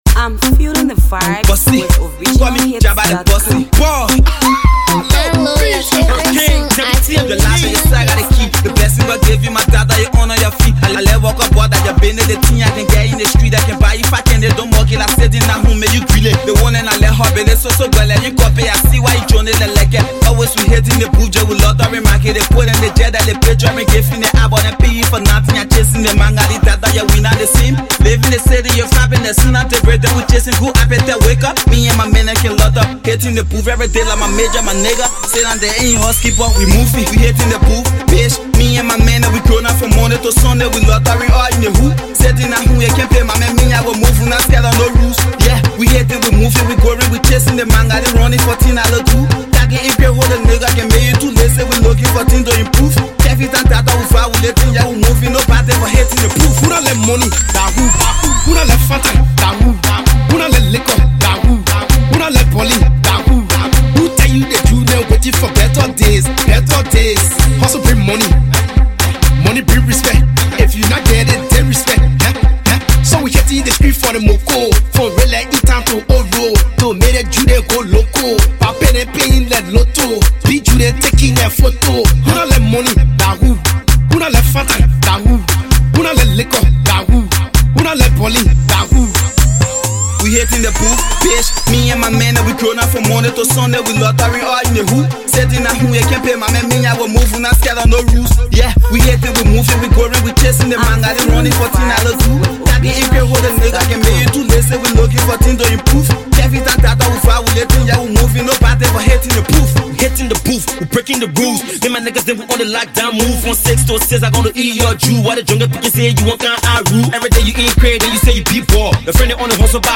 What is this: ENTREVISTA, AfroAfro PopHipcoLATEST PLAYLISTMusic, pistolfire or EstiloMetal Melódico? AfroAfro PopHipcoLATEST PLAYLISTMusic